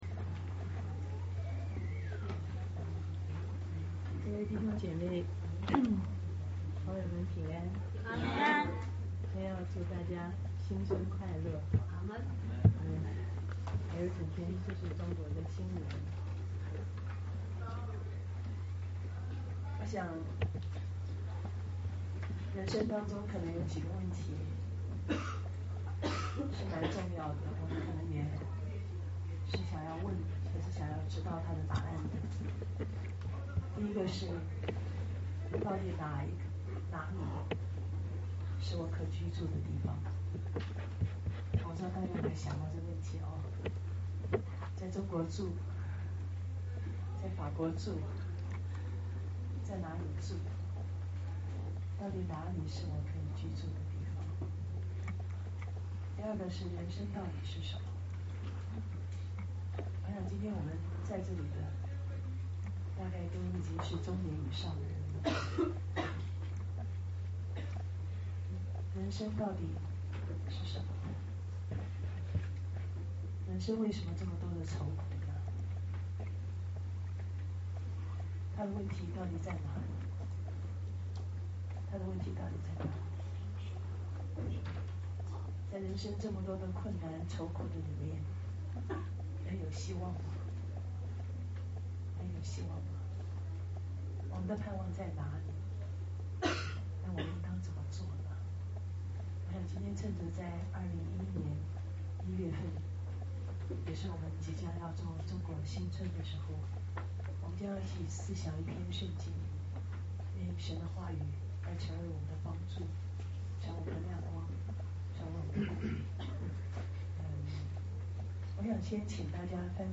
2011年1月30日巴黎温州教会国语团契讲道